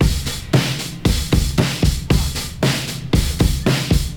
• 115 Bpm HQ Drum Loop Sample F# Key.wav
Free drum loop sample - kick tuned to the F# note. Loudest frequency: 2189Hz
115-bpm-hq-drum-loop-sample-f-sharp-key-dd4.wav